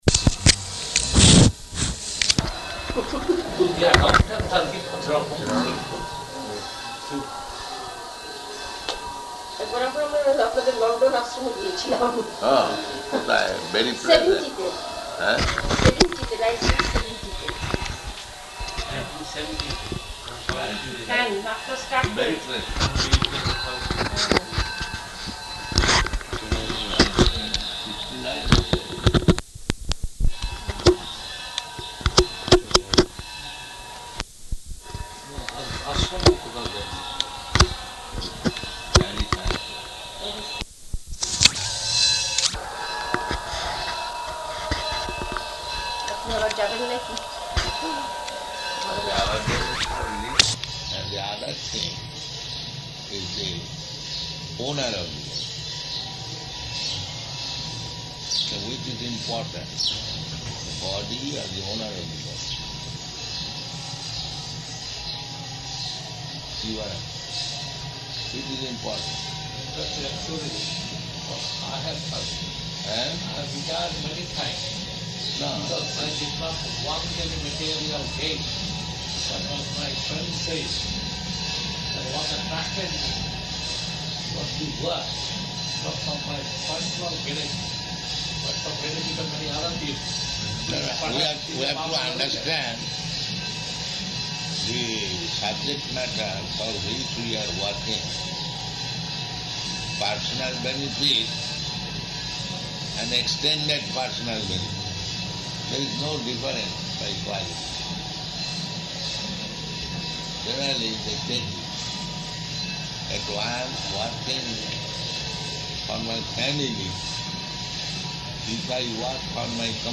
Evening Darśana --:-- --:-- Type: Conversation Dated: February 26th 1977 Location: Māyāpur Audio file: 770226ED.MAY.mp3 [Hindi] Prabhupāda: So this...